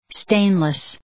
Shkrimi fonetik {‘steınlıs}